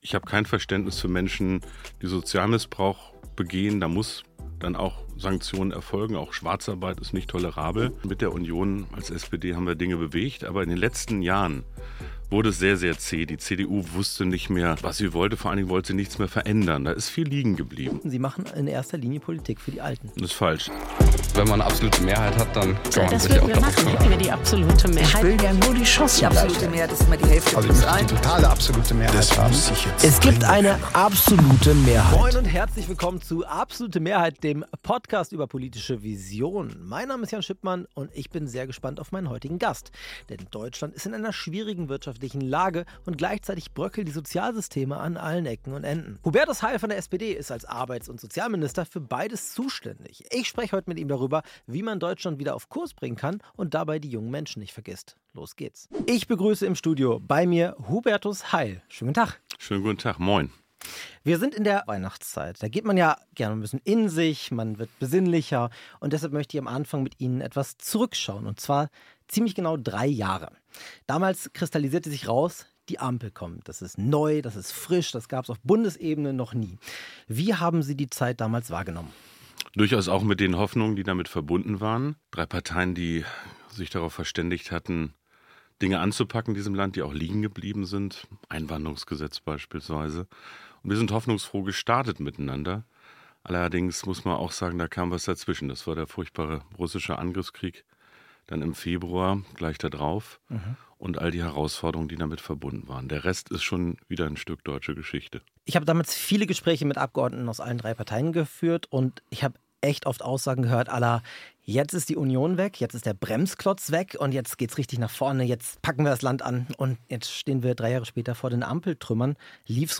Hubertus Heil (SPD) ist nicht nur Bundesminister für Arbeit und Soziales, sondern war auch schon in der Groko im Amt. Im Gespräch